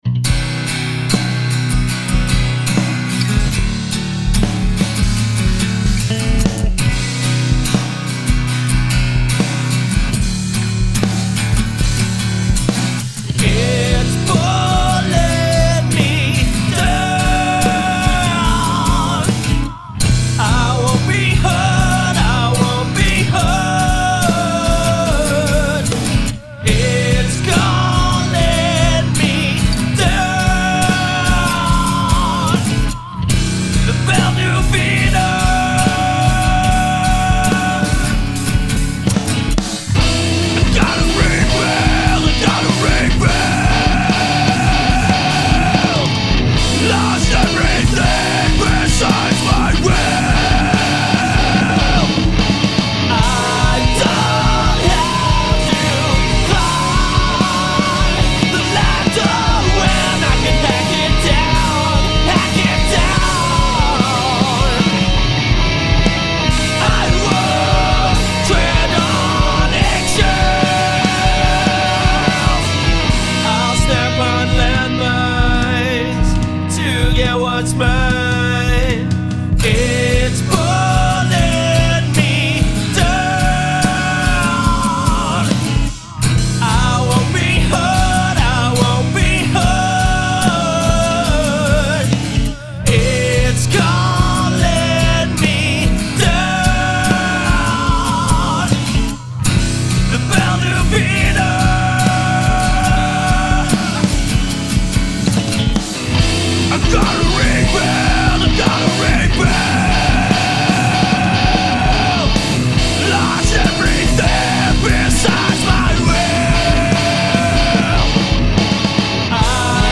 First off, I have tried putting a limiter on the kick and the snare.
My thinking is that if I am hitting somewhere between the 90's and newer recordings, that should be a perfectly acceptable range where they still sound "new(ish)" but retain an older aesthetic (and make it easier on my cheap equipment not to clip the hell out of a track lol)
Having said that I have my master fader set to -1 now, and with the limiter set with a -0.1 ceiling it's coming out at -1.1 :)
There are some nice parts to this....vocals sit quite well but there are some guitars in there that just sound set out the mix, Mostly those panned hard right. Timing is an issue on those early kicks.
Still that early guitar throwing it off early in the left channel.